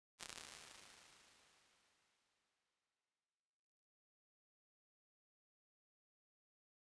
UVR_resources / impulse /VS8F-2 /215-R1_LongCave.wav
215-R1_LongCave.wav